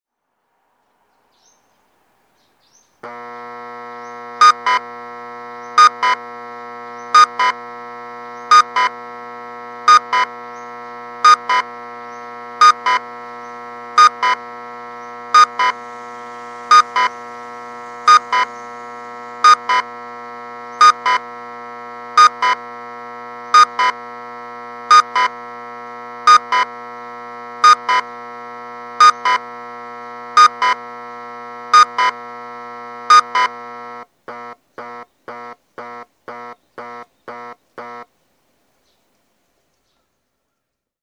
栄町1丁目(愛知県常滑市)の音響信号を紹介しています。